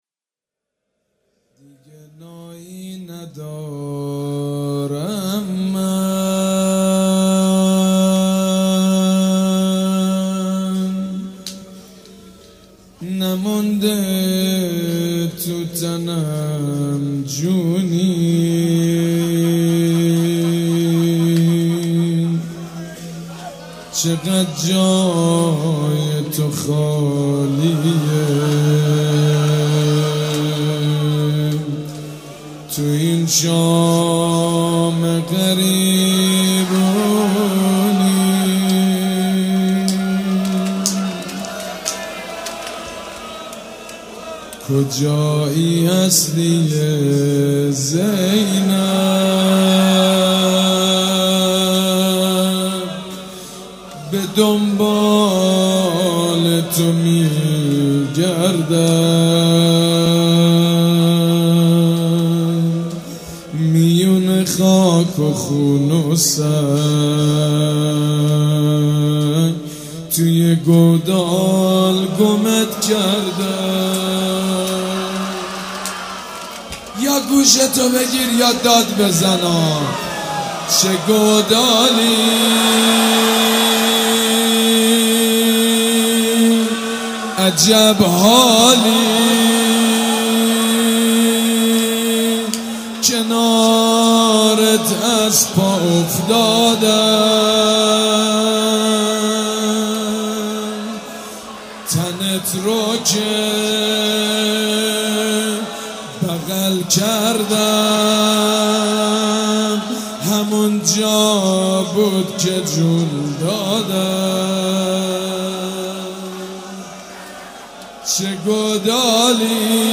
شب یازدهم محرم الحرام‌ چهارشنبه 21 مهرماه ۱۳۹۵ هيئت ريحانة الحسين(س)
روضه
مراسم عزاداری شب شام غریبان